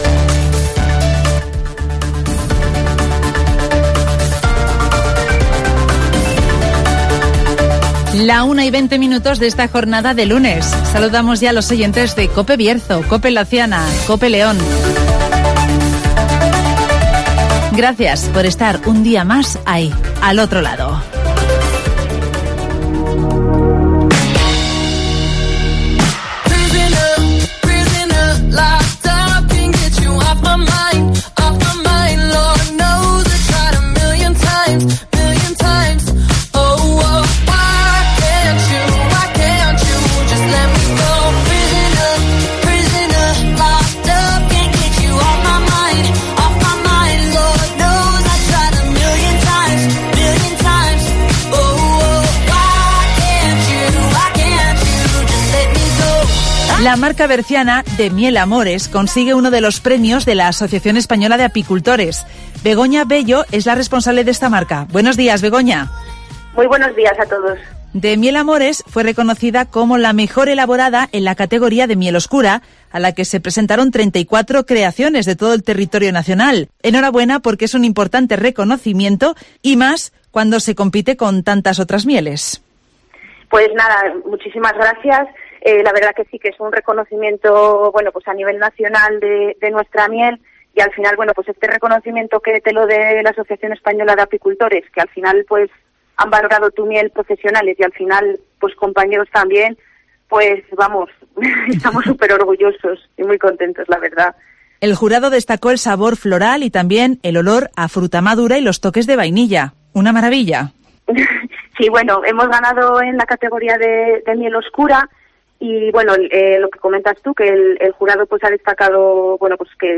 La marca berciana ‘De miel amores’ consigue uno de los premios de la Asociación Española de Apicultores (Entrevista